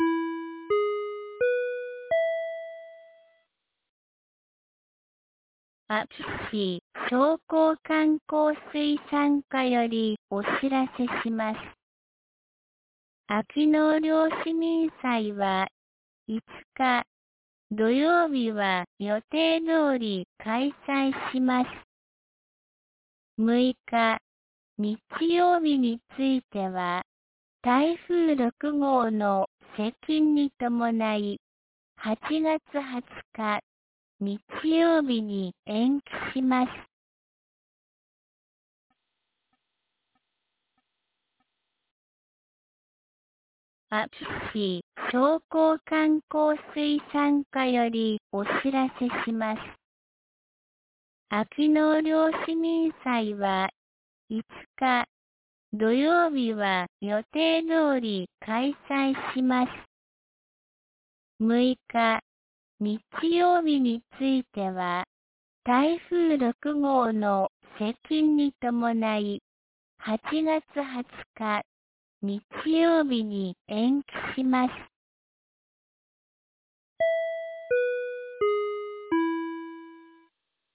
2023年08月04日 17時16分に、安芸市より全地区へ放送がありました。